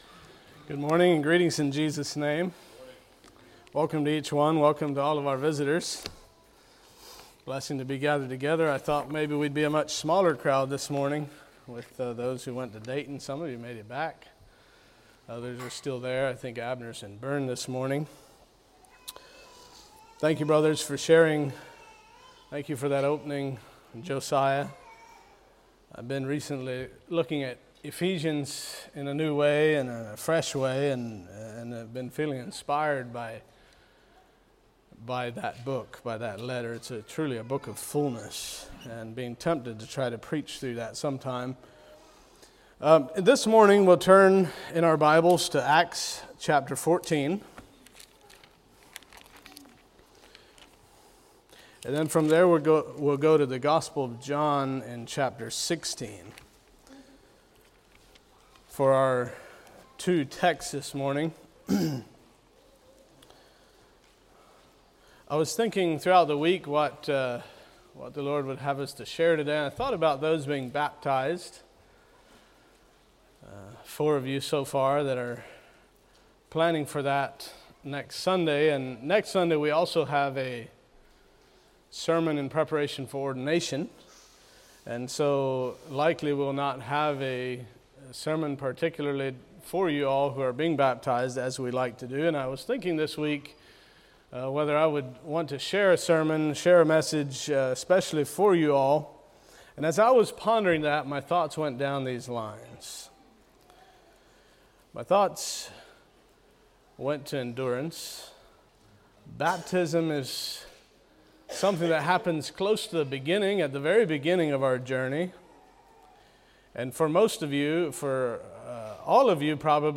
Sermons of 2025 - Blessed Hope Christian Fellowship